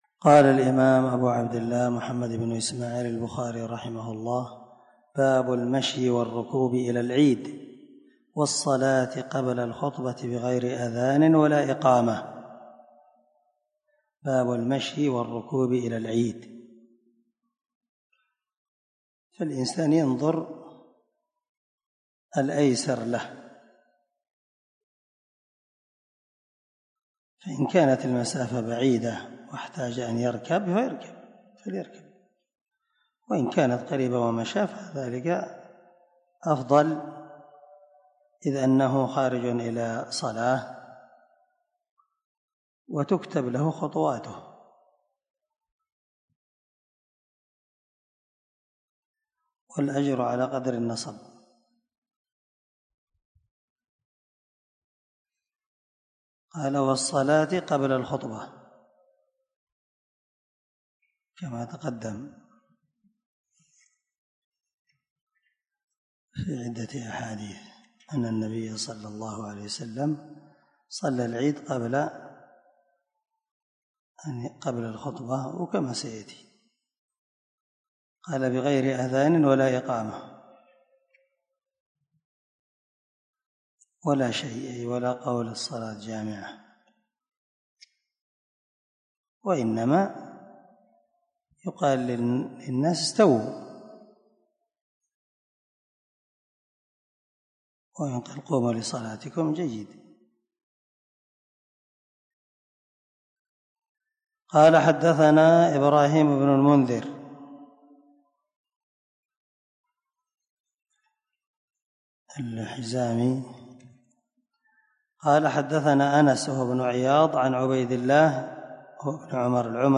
دار الحديث- المَحاوِلة- الصبيحة.